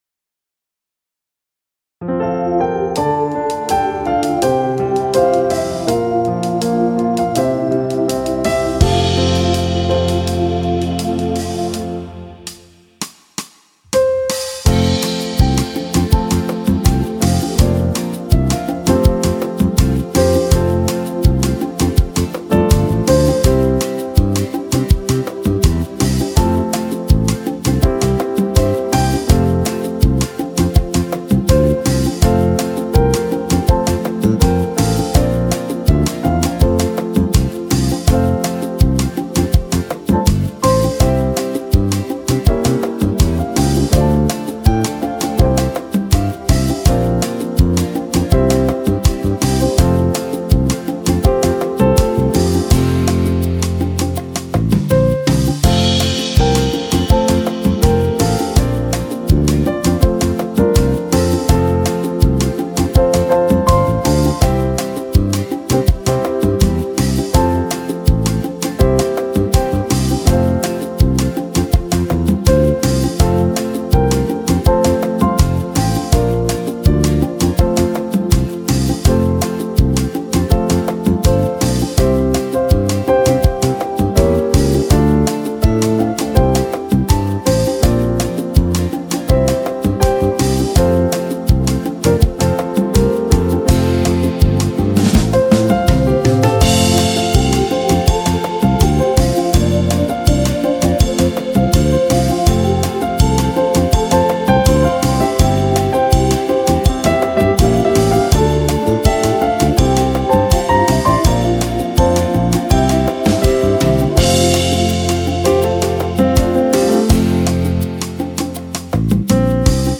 Cantiques (Siège de Porto-Novo)